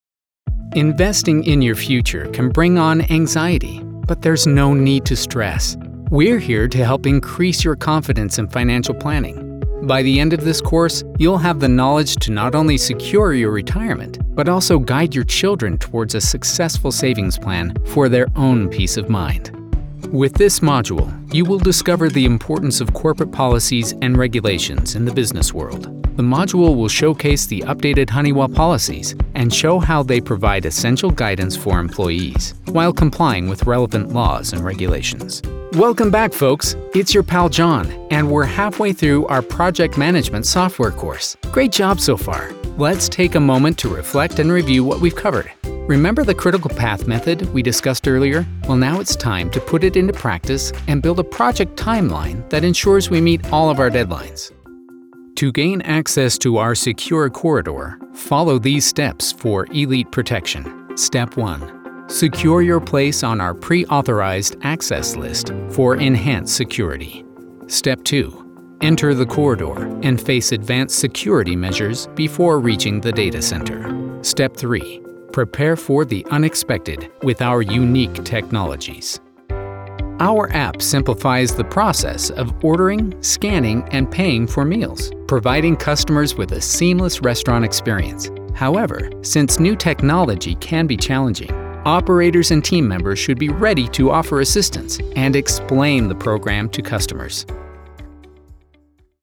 eLearning Demo
Relatable, Clear, Natural